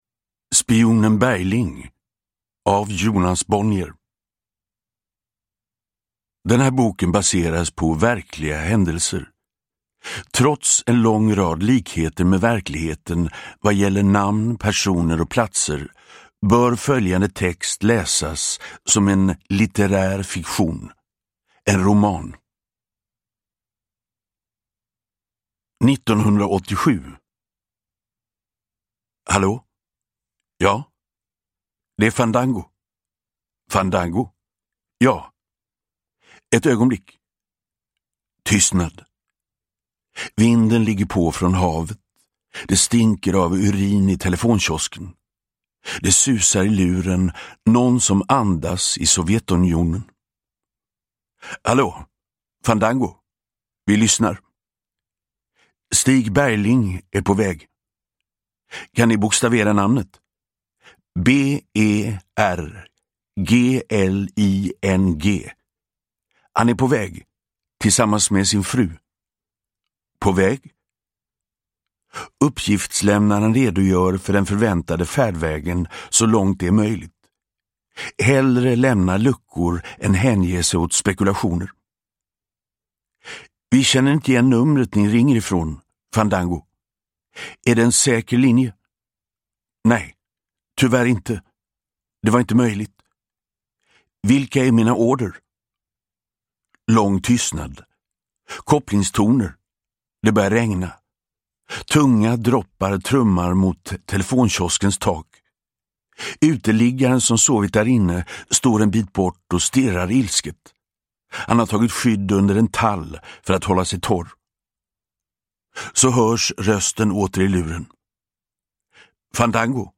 Uppläsare: Magnus Roosmann
Ljudbok